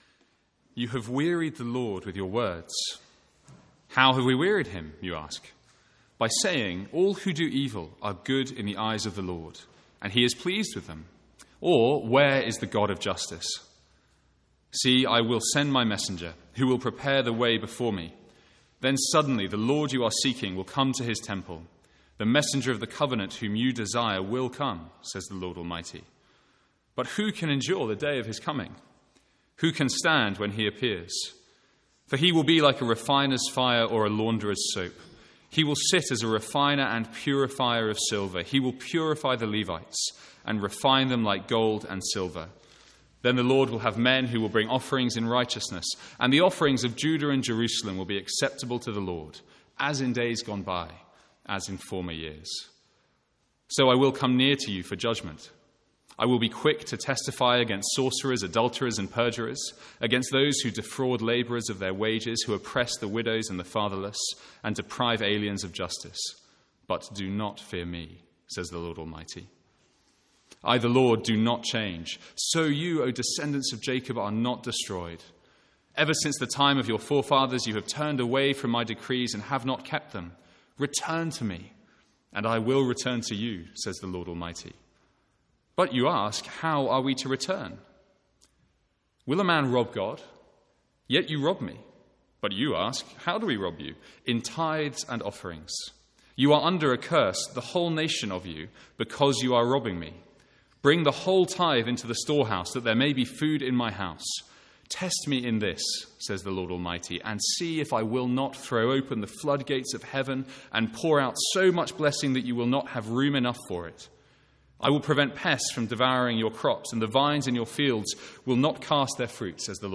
From the Sunday morning series in Malachi.